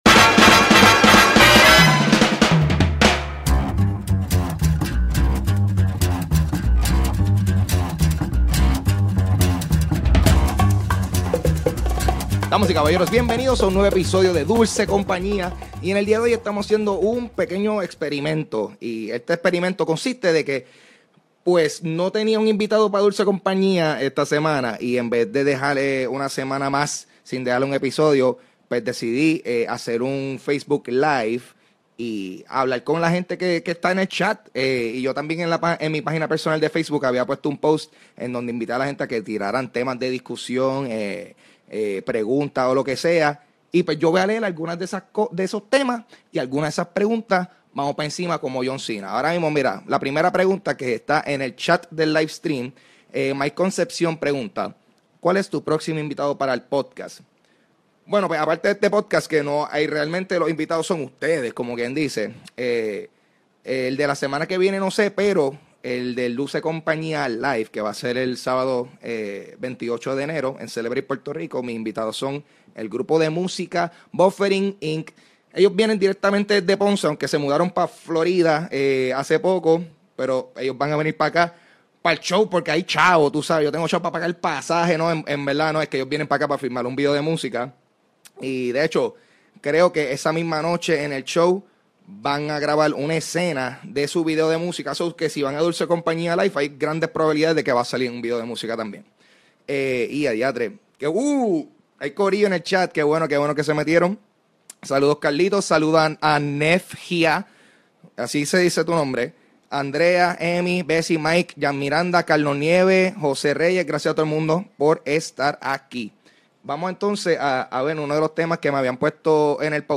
Hice un "livestream" en mi Facebook donde contesto preguntas y hablo sobre el Nintendo Switch, Stranger Things, Discovery Zone, el mejor momento para robar un beso y mucho más.